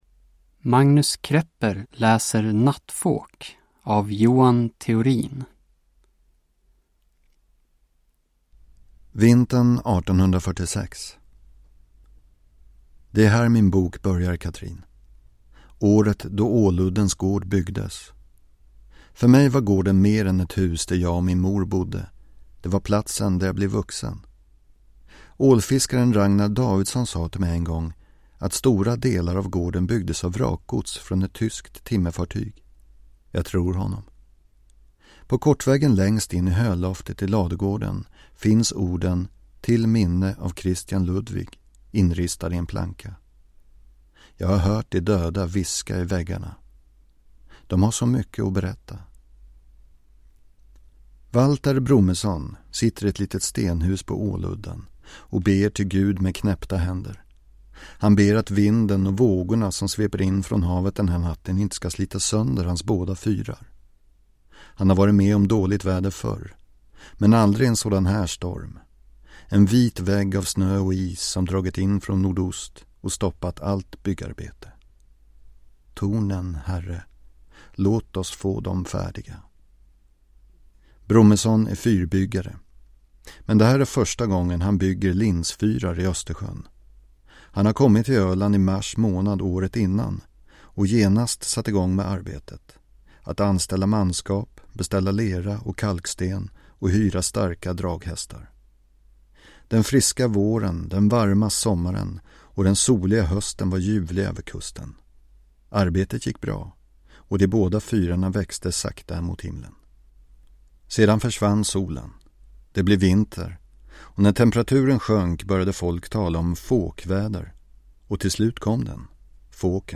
Uppläsare: Magnus Krepper
Ljudbok